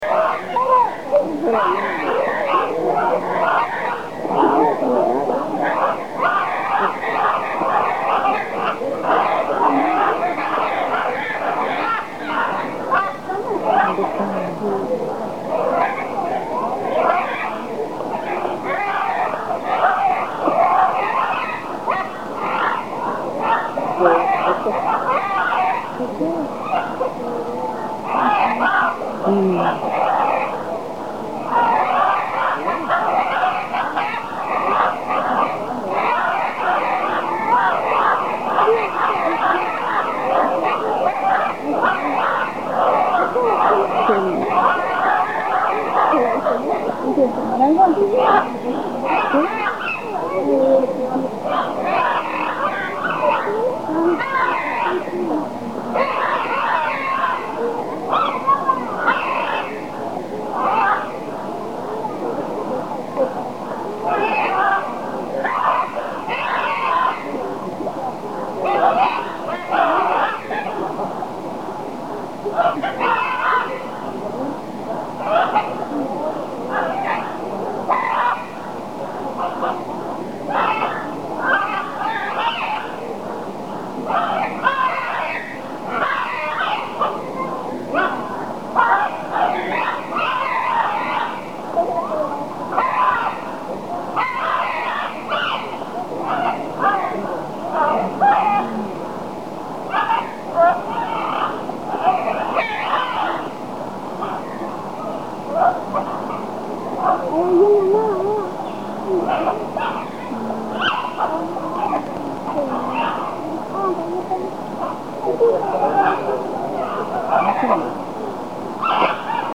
Guacamayas
Grabación realizada en el área de las Guacamayas el 11 de julio en las instalaciones del Zoológico Miguel Álvarez del Toro (ZOOMAT), ubicado en una reserva de aproximadamente 100 hectáreas de selva semihúmeda conocida como El Zapotal, en el lado sur oriente de Tuxtla Gutierrez, Chiapas.